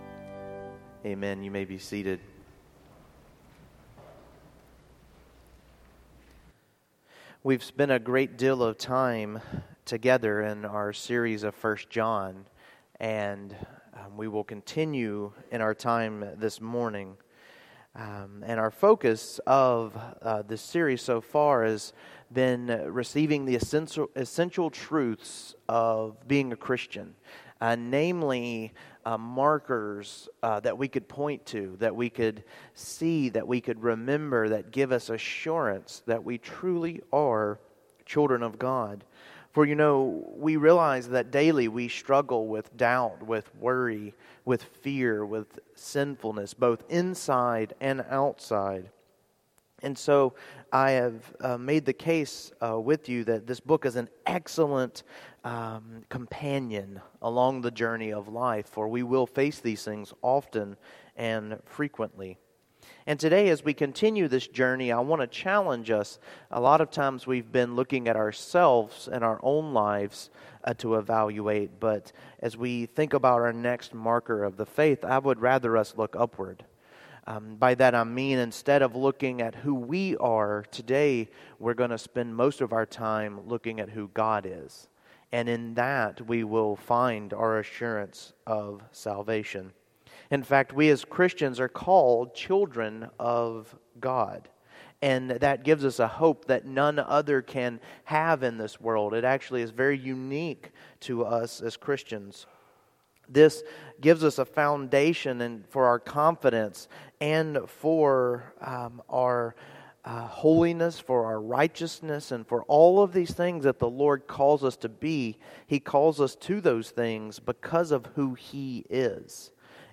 1 John 2:28-3:10 Service Type: Morning Worship I. We have hope as Children of God